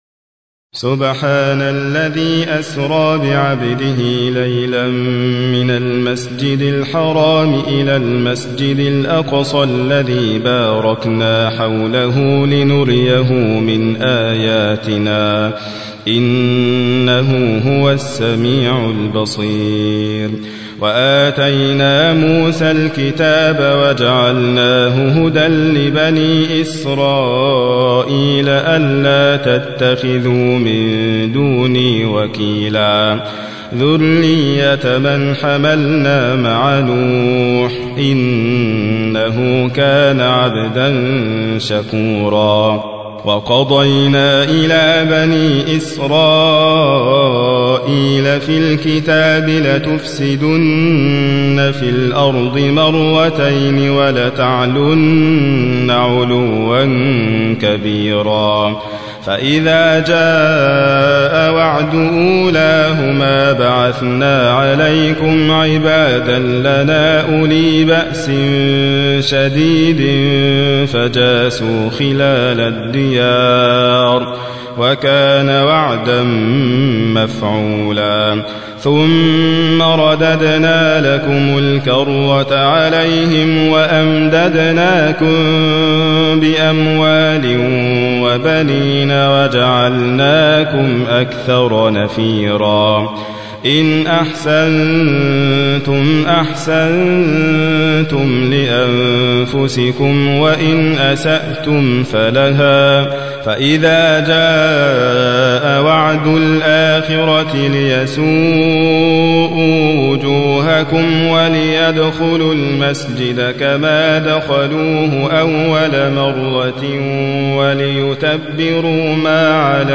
تحميل : 17. سورة الإسراء / القارئ حاتم فريد الواعر / القرآن الكريم / موقع يا حسين